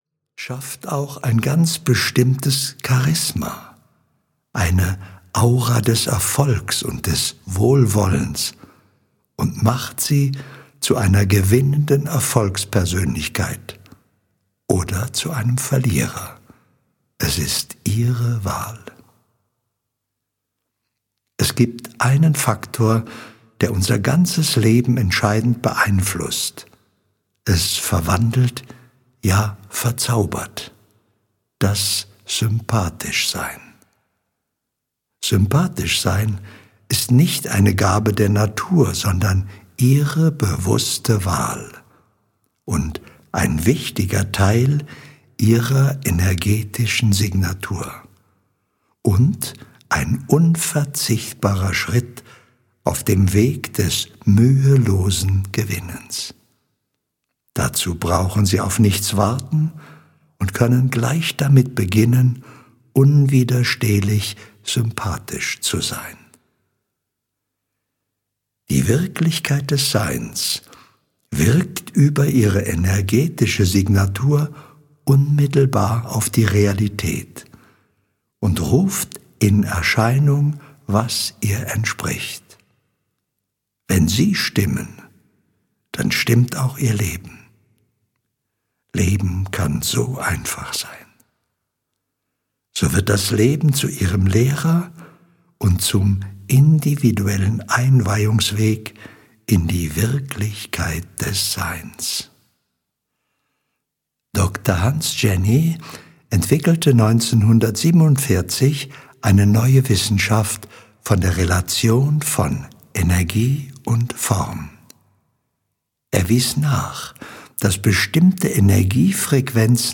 Alltagsrezepte: Wie man das Leben steuert - Hörbuch